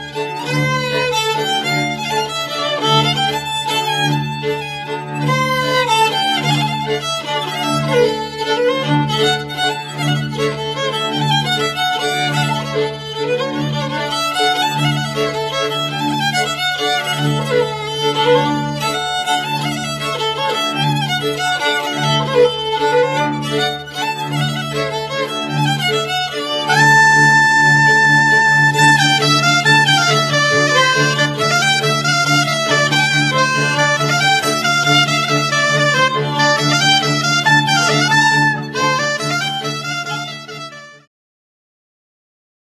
basy bass, śpiew voice